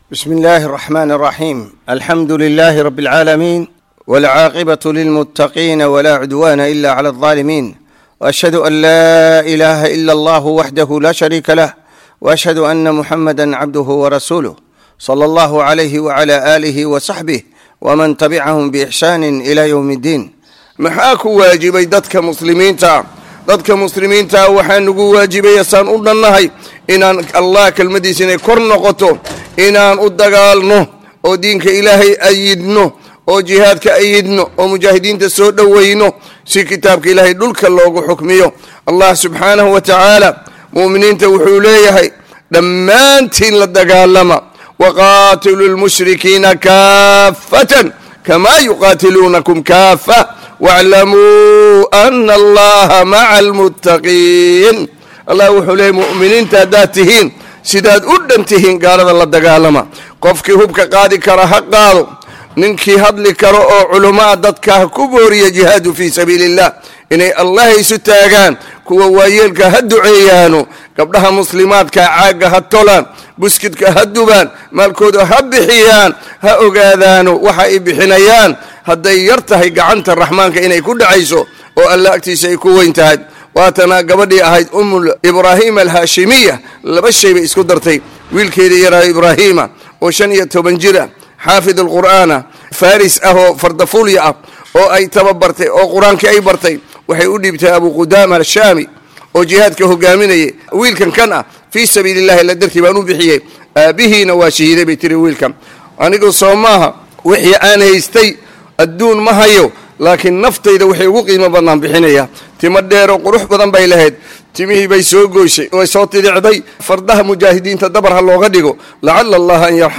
Habada halkan hoose ka dhegeyso kalimadda Sheekha.